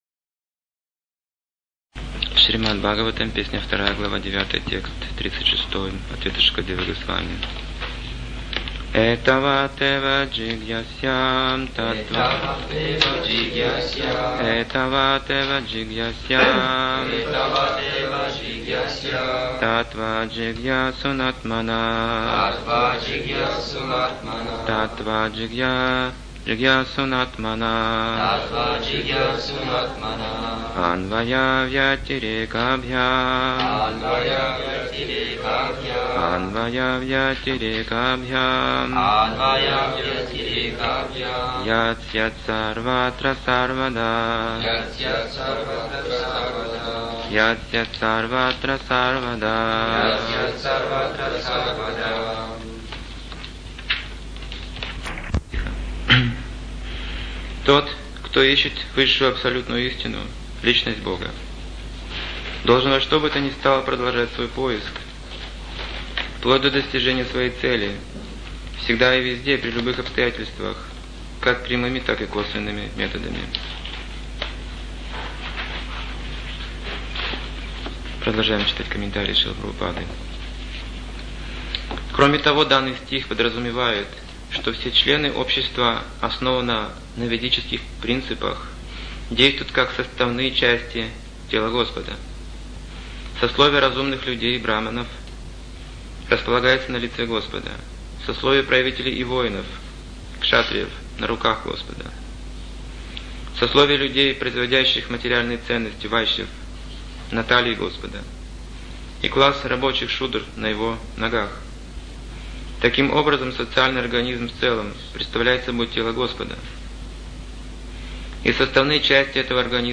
Темы, затронутые в лекции: Искаженное восприятие мира Варнашрама дхарма Наука о брахмане Причина разногласий Вкус проповеди Принципы чистоты История Мучукунды Осознание брахмана Прямые и косвенные методы Могущество звука Уровни преданных Благословение Прабхупады